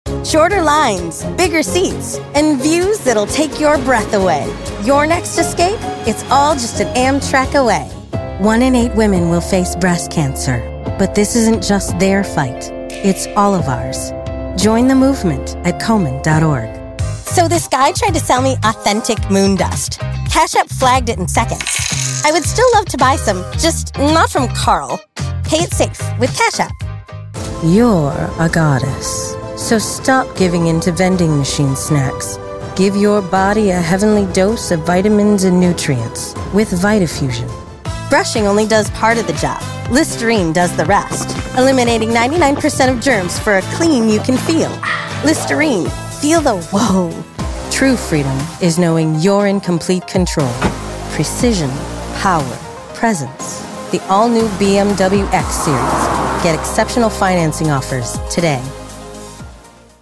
Voice Artist
Enhance your commercials and narrations with a confident, warm, engaging voice.